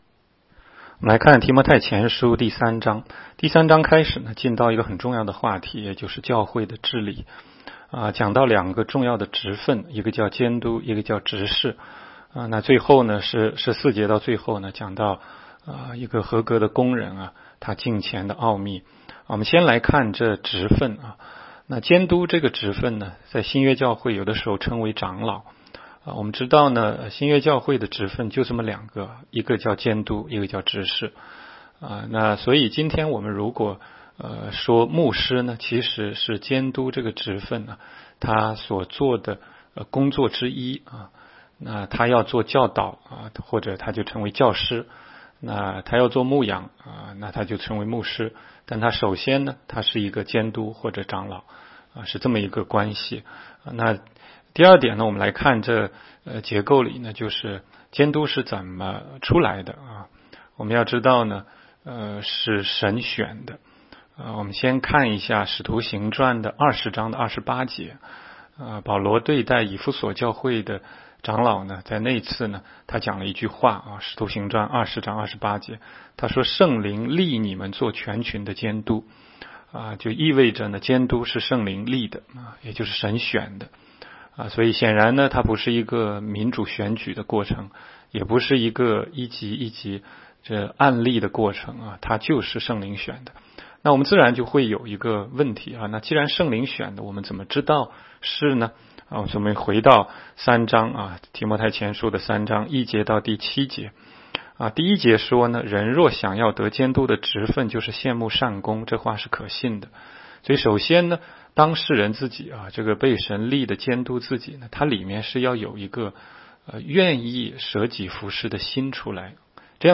16街讲道录音 - 每日读经-《提摩太前书》3章